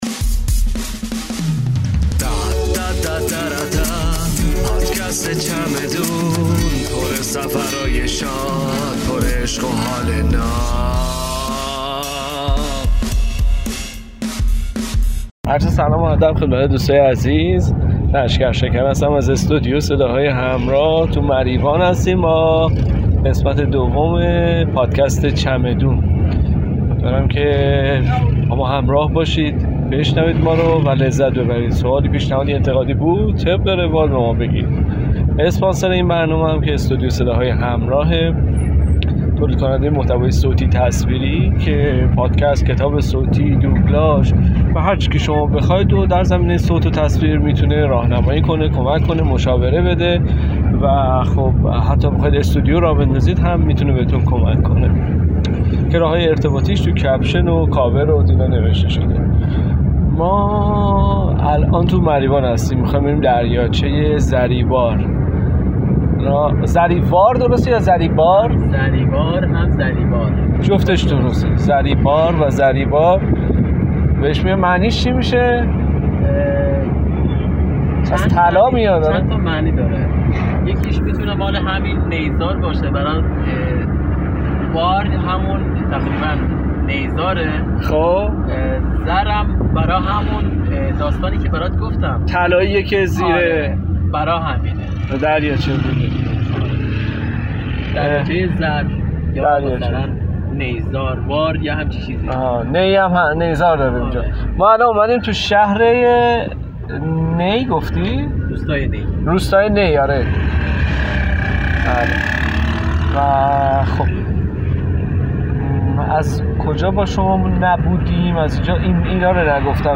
ضبط شده در سفر بصورت لایو